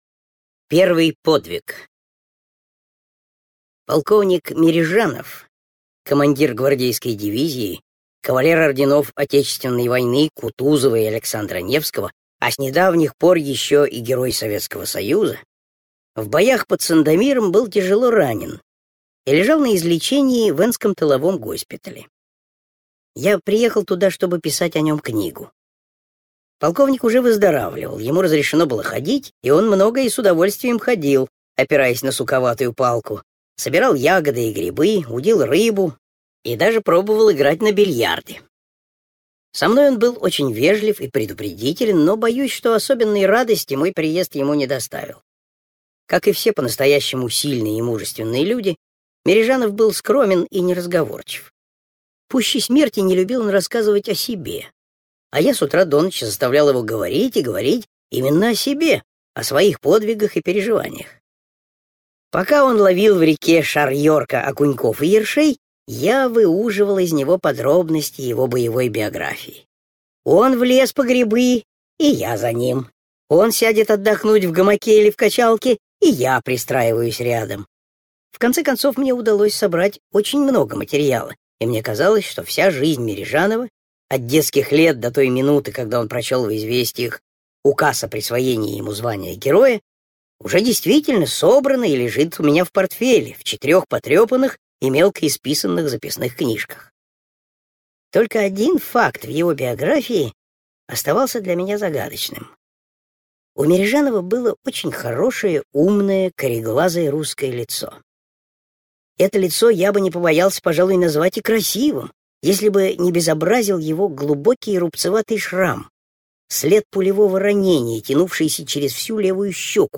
Первый подвиг - аудио рассказ Пантелеева - слушать онлайн